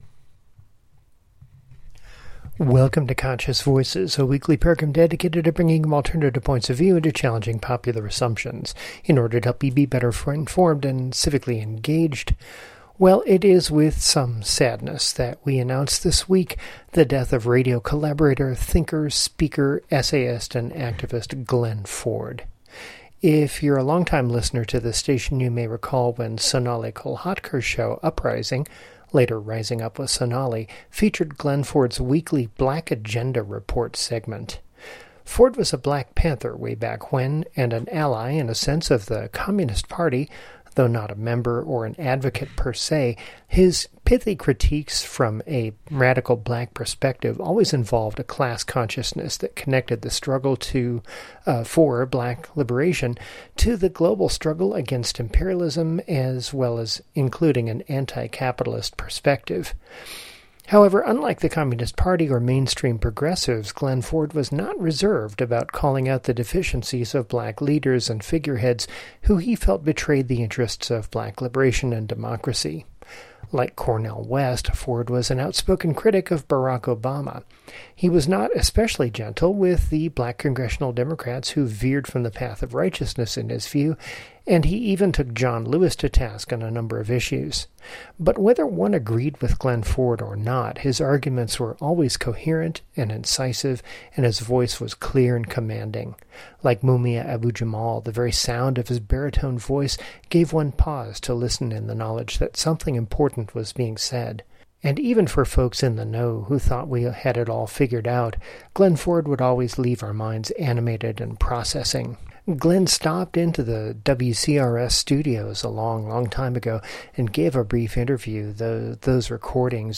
Glen Ford, radio commentator and author , co-founder of the Black Commentator, and the Black Agenda Report has passed away at the age of 71. In today's program we'll hear a speech he gave in 2020 on the occasion of the 14th anniversary of the founding of the Black Agenda Report.